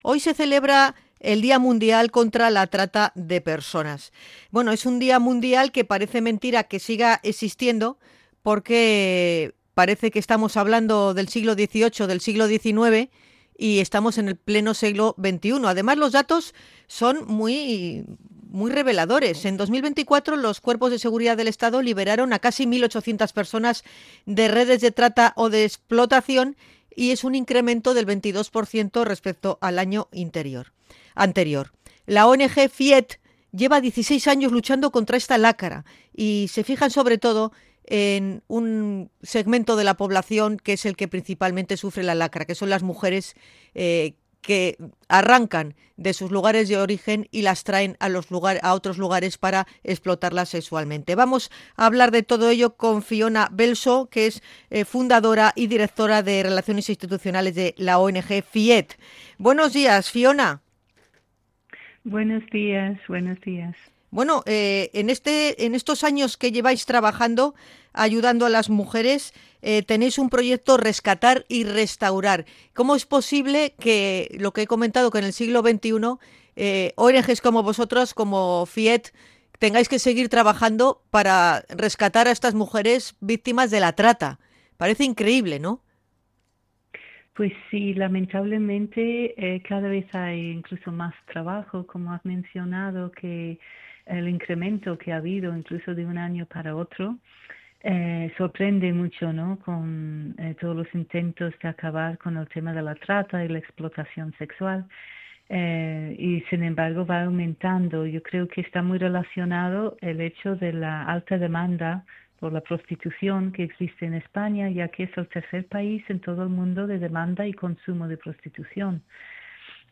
INT.-DIA-MUNDIAL-CONTRA-LA-TRATA-DE-PERSONAS.mp3